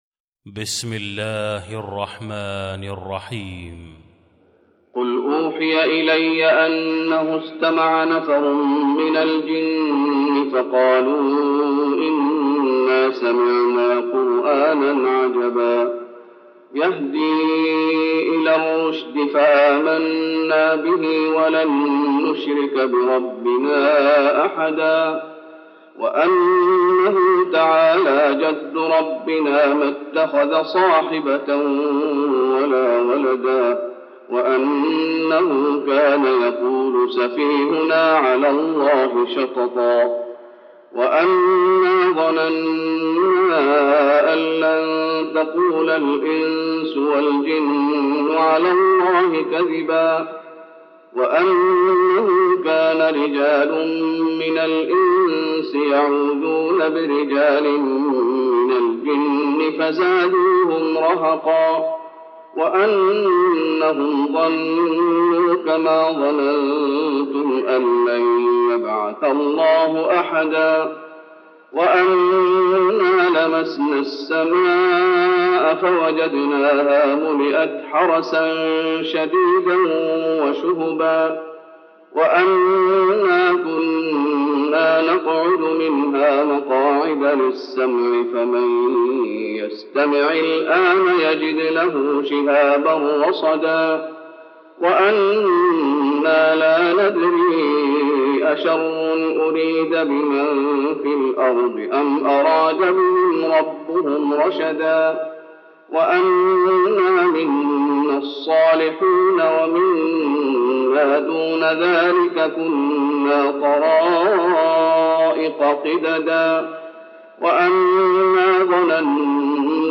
المكان: المسجد النبوي الجن